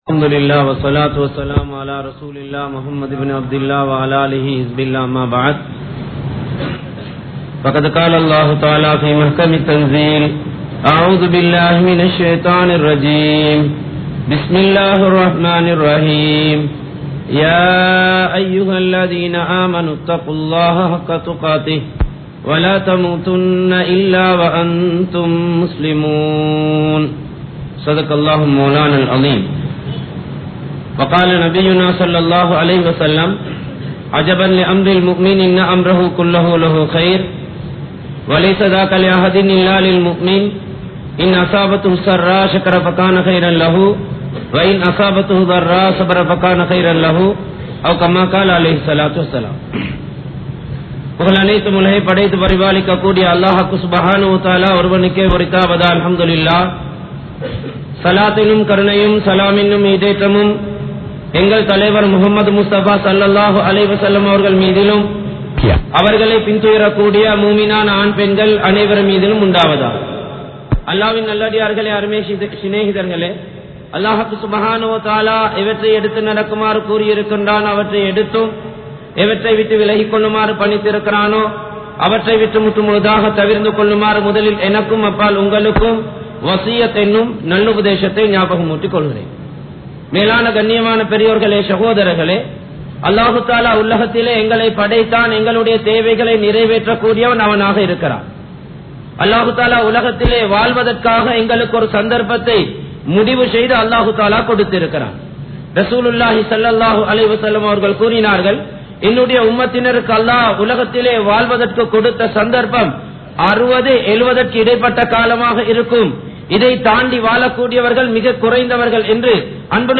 Thaqwa Ulla Manitharhalin Nilai(தக்வா உள்ள மனிதர்களின் நிலை) | Audio Bayans | All Ceylon Muslim Youth Community | Addalaichenai
Colombo 03, Kollupitty Jumua Masjith